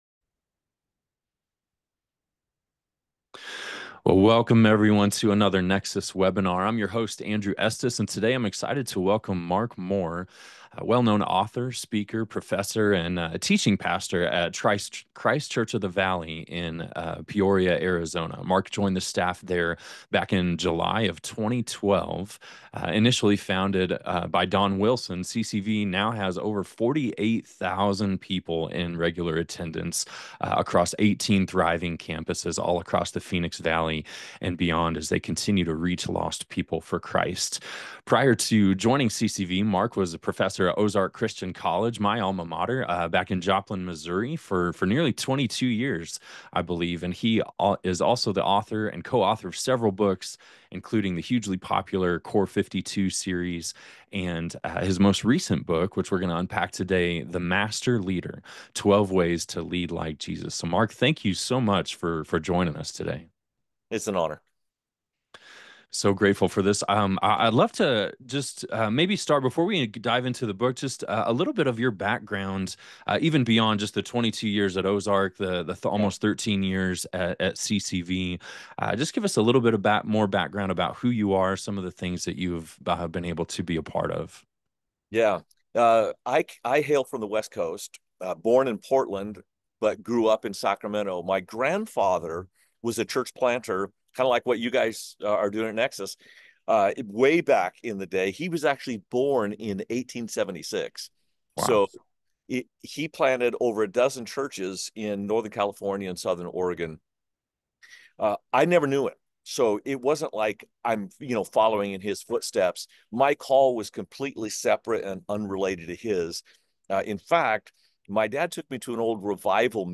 Join us for an exclusive webinar